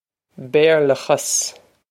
Béarlachas Bare-la-khas
This is an approximate phonetic pronunciation of the phrase.